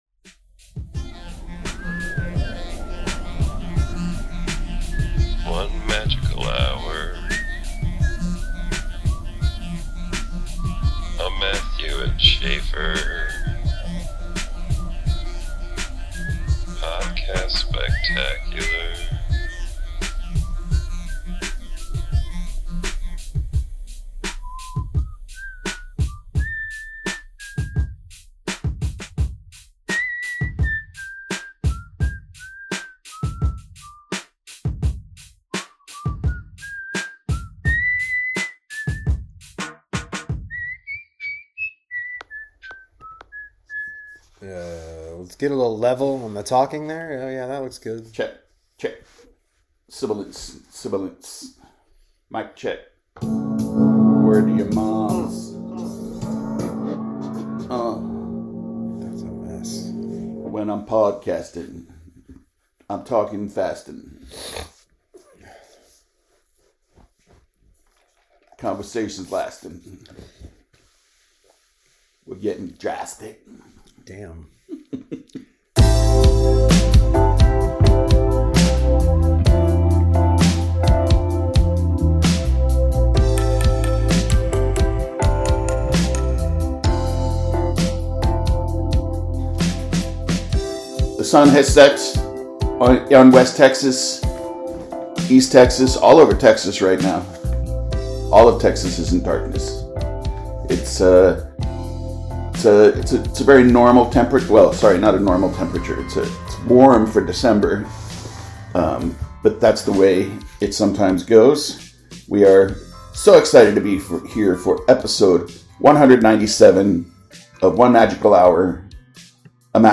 (Sorry about the audio!)